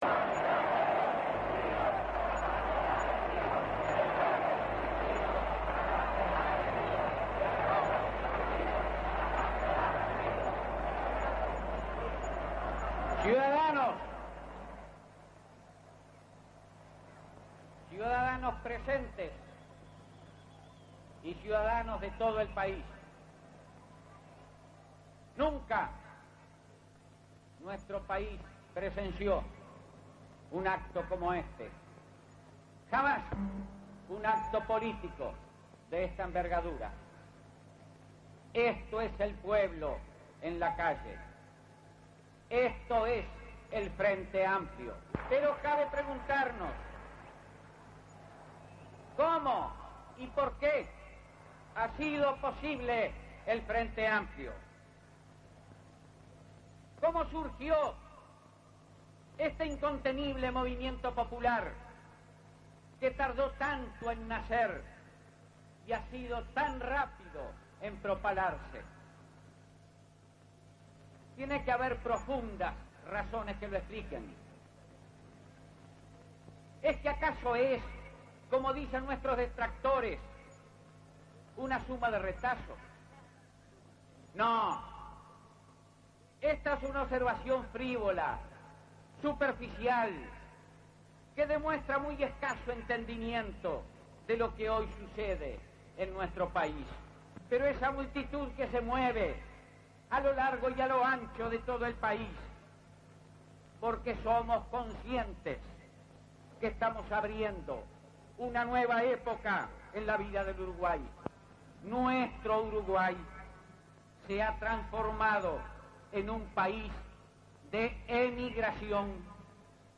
Discurso del General Liber Seregni en la explanada de la Intendencia de Montevideo en el primer acto de masas del Frente Amplio.